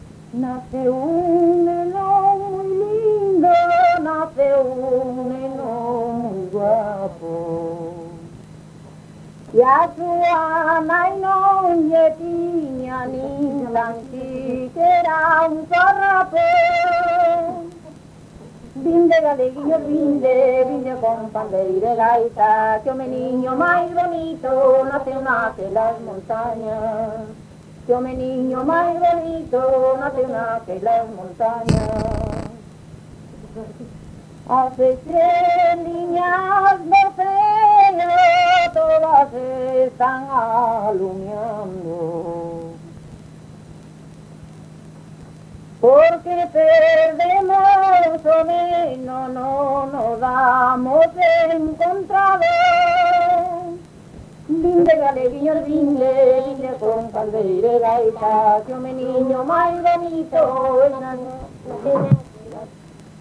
Tipo de rexistro: Musical
Áreas de coñecemento: LITERATURA E DITOS POPULARES > Cantos narrativos
Lugar de compilación: Chantada - A Grade (San Vicente) - Quintá
Soporte orixinal: Casete
Instrumentación: Voz
Instrumentos: Voz feminina